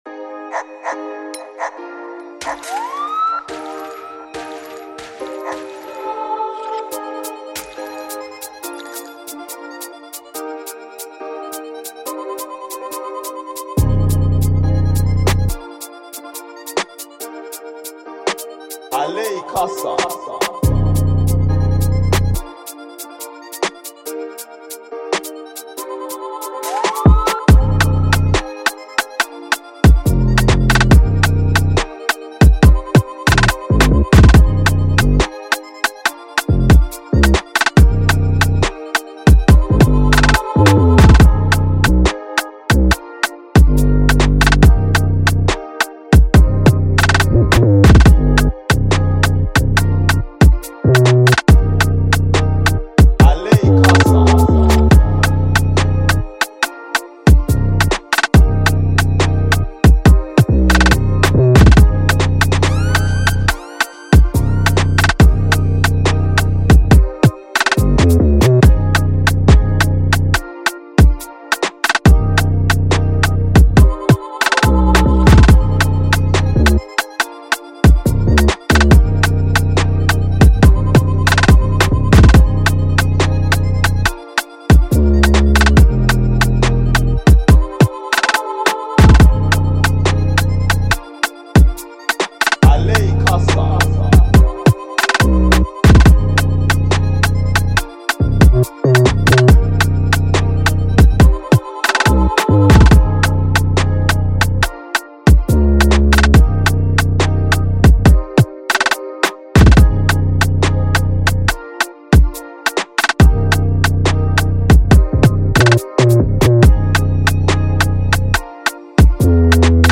UK Drill instrumental
The Drill Freebeat